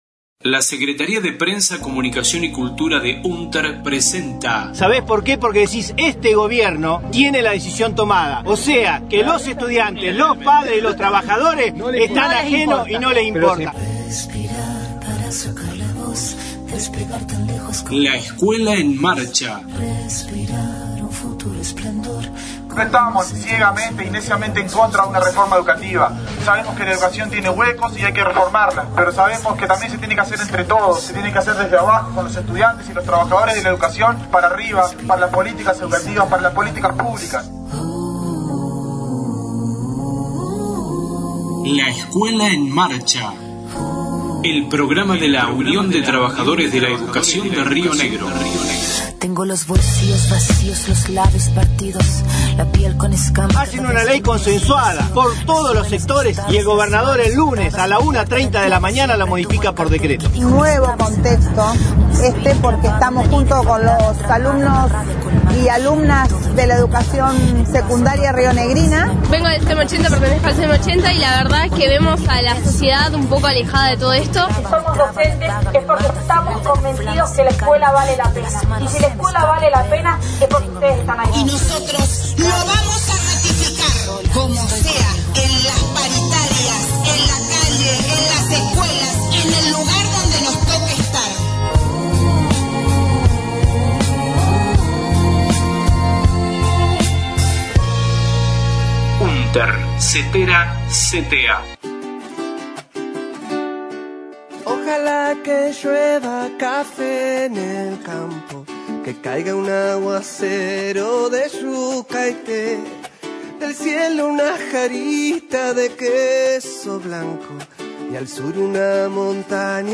La Escuela en Marcha La Escuela en Marcha Radio Congreso Pedagogías y Sindicato